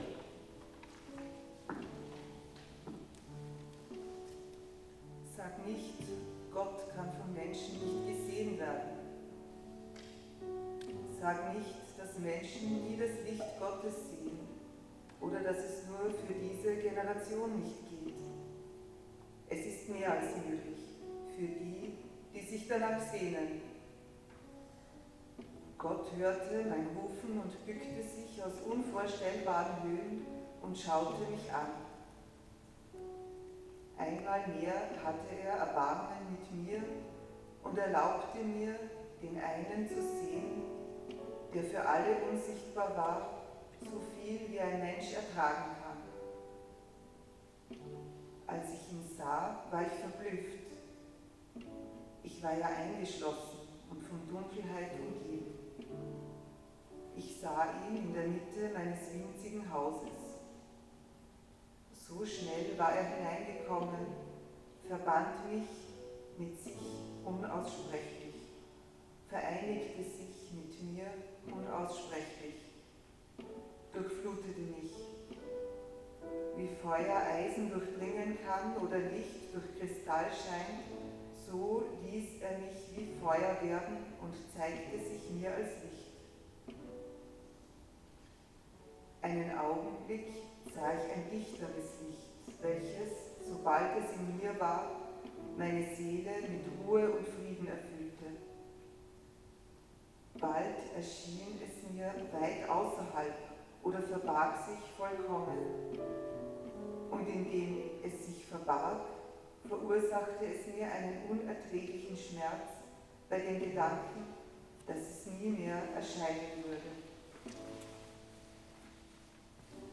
Unio ist eine Veranstaltungsreihe: Ein Mix aus Lesung alter Texte und moderner meditativer Musik soll Menschen in die Stille führen.